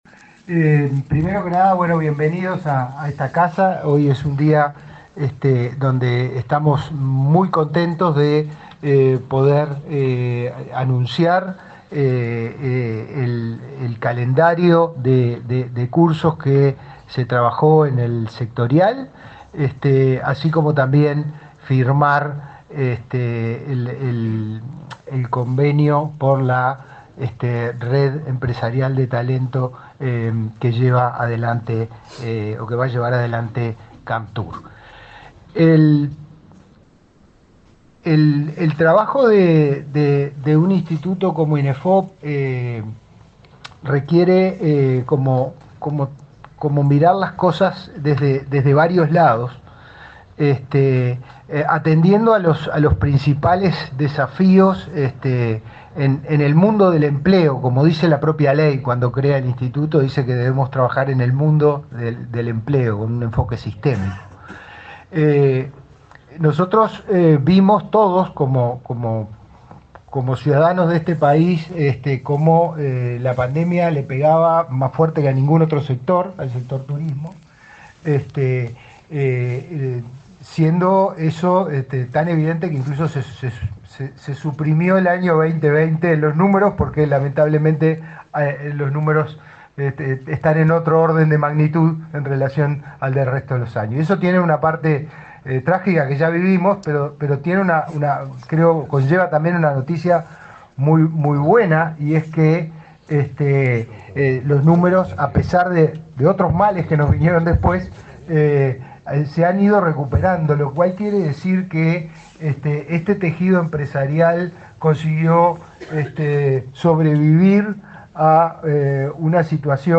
Palabras de autoridades en acto de Inefop
Palabras de autoridades en acto de Inefop 17/07/2024 Compartir Facebook X Copiar enlace WhatsApp LinkedIn El director del Instituto Nacional de Empleo y Formación Profesional (Inefop), Pablo Darscht, y el subsecretario de Trabajo, Daniel Pérez, participaron, este miércoles 17 en Montevideo, en el acto de lanzamiento de cursos de formación vinculados al sector turístico.